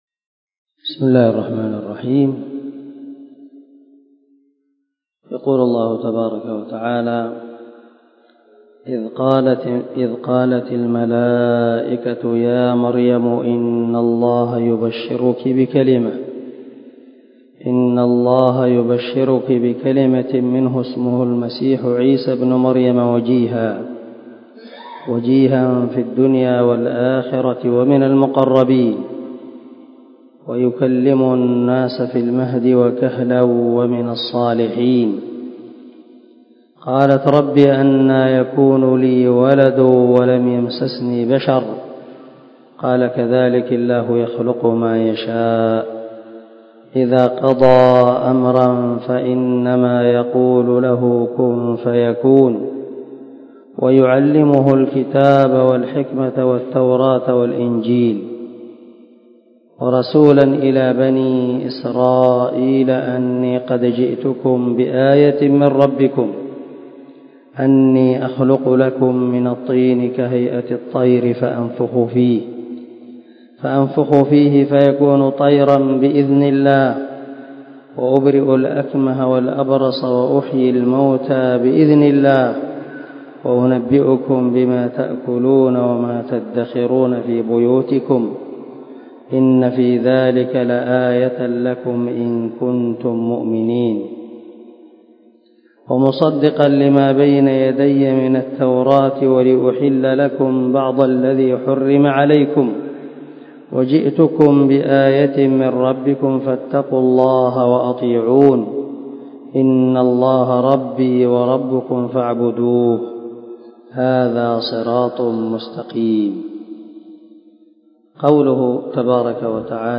170الدرس 15 تفسير آية ( 45 – 51 ) من سورة آل عمران من تفسير القران الكريم مع قراءة لتفسير السعدي
دار الحديث- المَحاوِلة- الصبيحة.